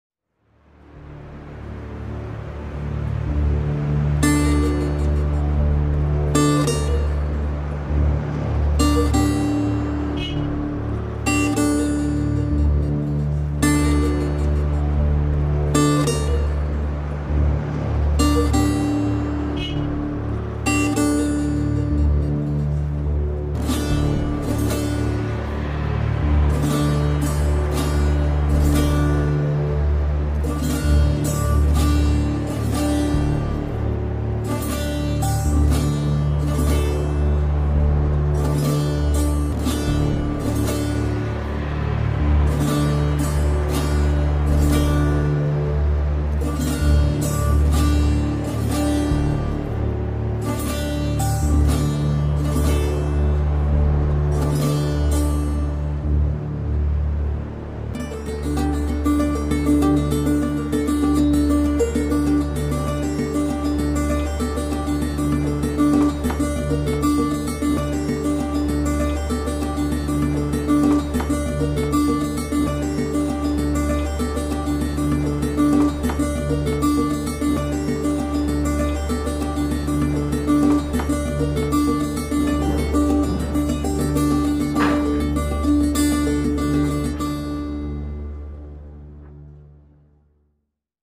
tema dizi müziği, duygusal hüzünlü gerilim fon müziği.